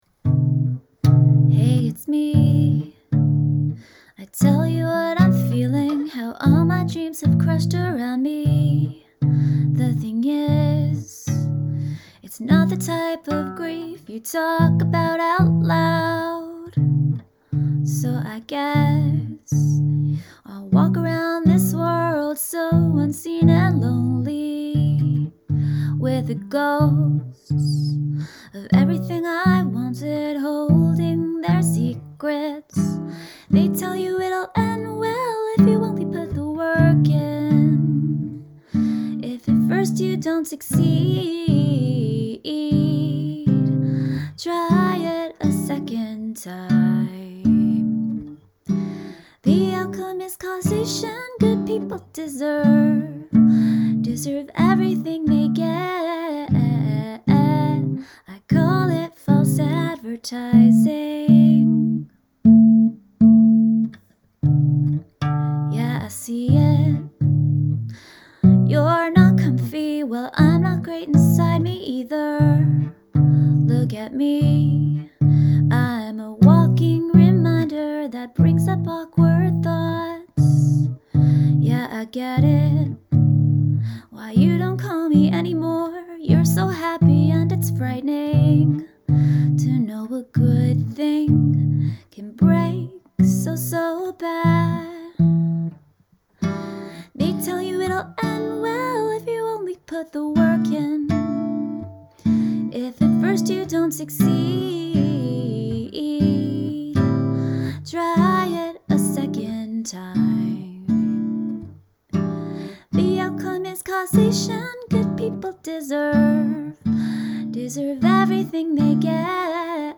You sound natural and meaningful. Beautiful singing and playing.
The sparse accompaniment is really cool too and fits great.
I'm such a fan of your voice, this minimalist instrumentation really leaves room for your interpretation, it's a sweet blend, delicious!
Really difficult note changes in this and you sing every note perfectly! Lovely jazzy chords, too.